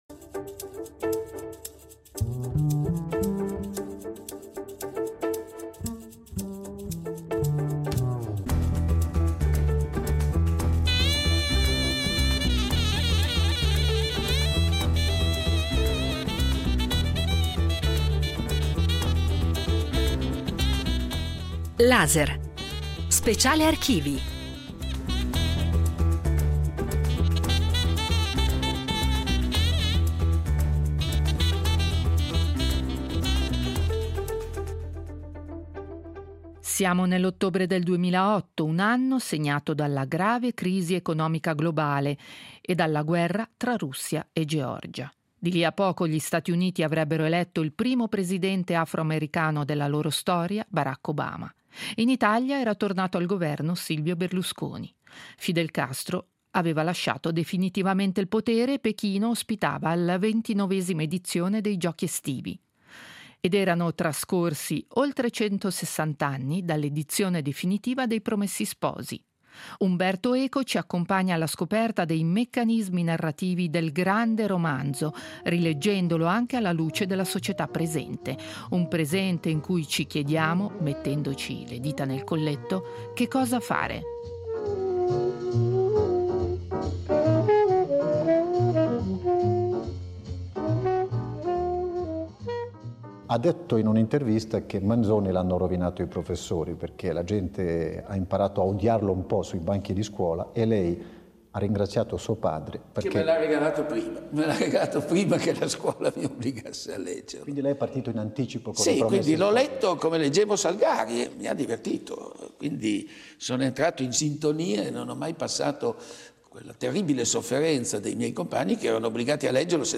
Il 19 febbraio ricorrono i dieci anni dalla scomparsa di Umberto Eco , il grande semiologo, filosofo, medievista, narratore e massmediologo, che ha segnato la cultura del nostro tempo. Il modo migliore per ricordarlo è attraverso la sua stessa voce.